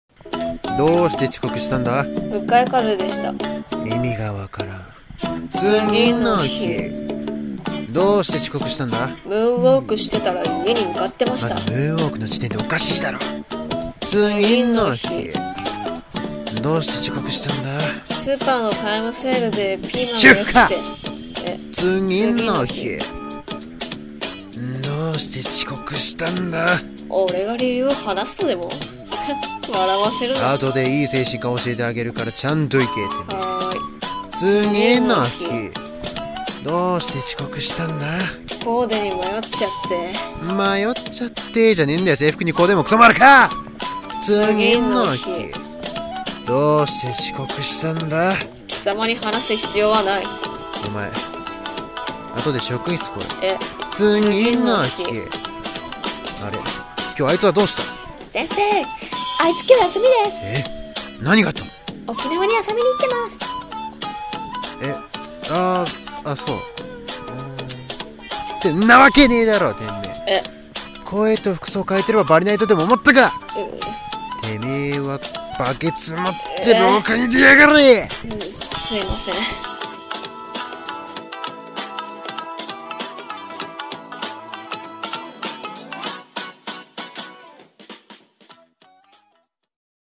声劇「遅刻の理由」